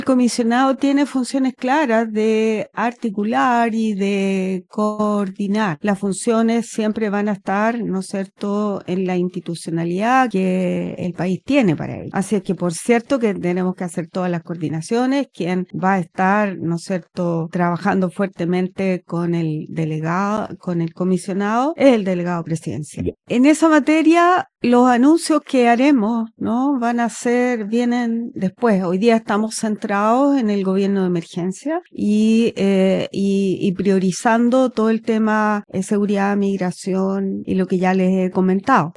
En entrevista exclusiva con Radio Paulina, la delegada presidencial regional de Tarapacá, Adriana Tapia Cifuentes, abordó la instalación del gobierno del Presidente José Antonio Kast en la región y los principales desafíos que enfrentará la nueva administración.